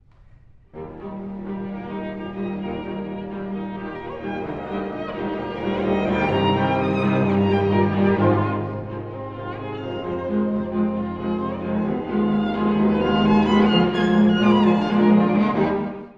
↑古い録音のため聴きづらいかもしれません！（以下同様）
Allegro moderato con fuoco
～ほどよく快速に　火のように生き生きと～
ほとばしるような情熱の波を感じます。
内声のさざ波、低弦のシンコペーションに誘われ、1stの活気的な上昇音型で幕を開けます。
展開部でややダークな雰囲気が流れるも、温度感は熱いまま、再現部・コーダでは再び活動的になります。